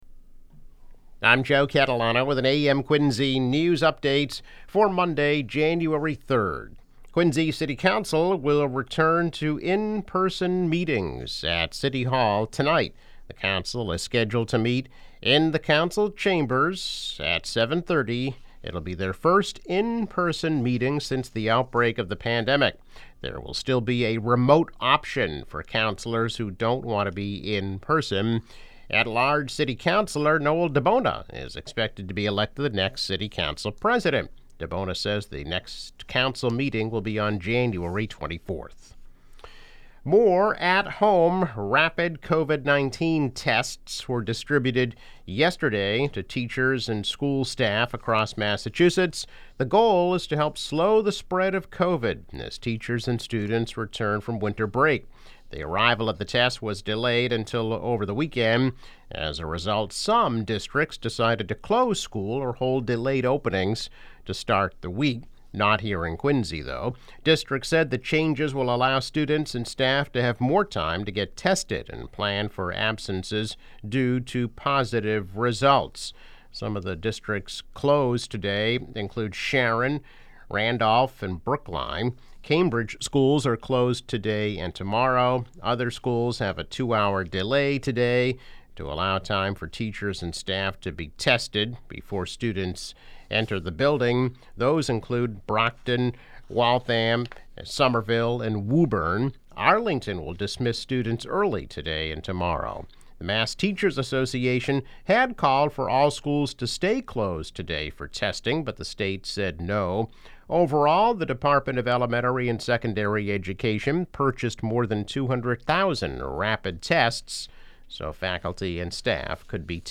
Daily news, weather and sports update.